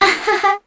Kara-Vox_Happy1_kr.wav